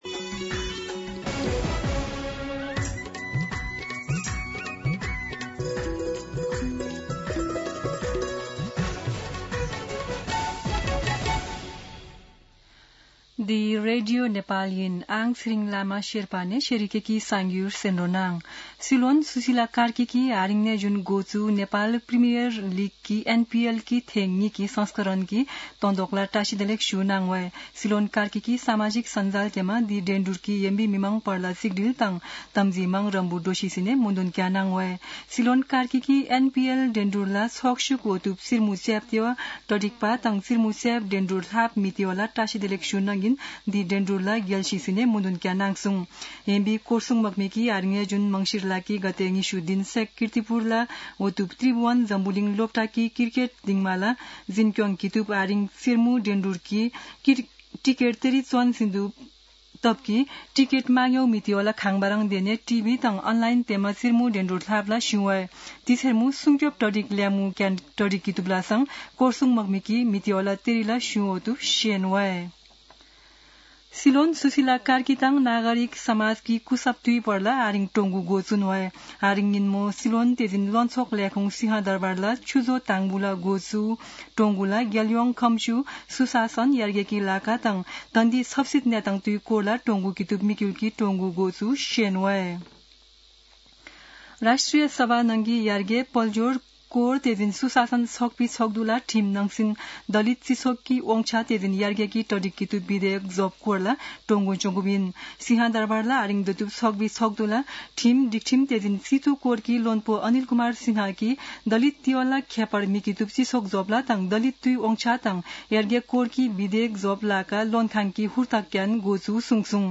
शेर्पा भाषाको समाचार : १ मंसिर , २०८२
Sherpa-News-8-1.mp3